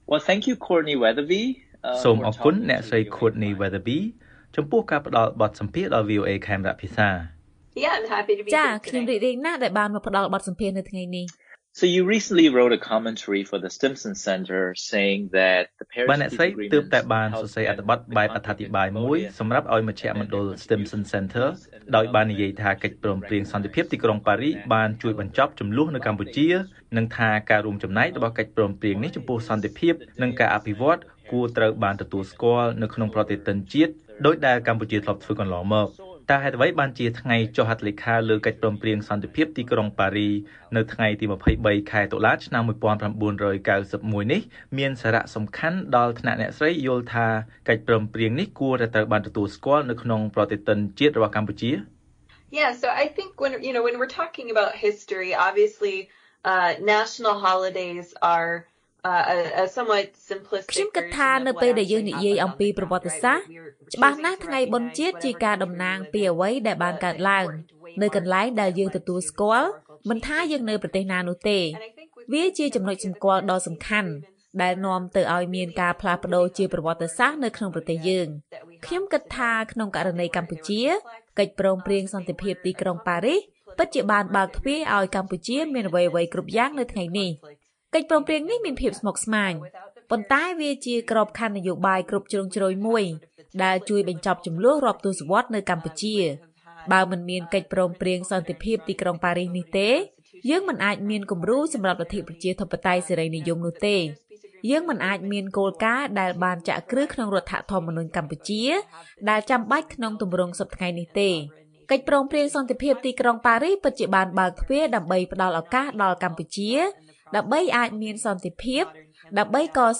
បទសម្ភាសន៍ VOA៖ អ្នកស្រាវជ្រាវអាមេរិកាំងថាថ្ងៃ ២៣ តុលា ១៩៩១ គួរត្រូវបានចងចាំក្នុងប្រតិទិនជាតិរបស់កម្ពុជា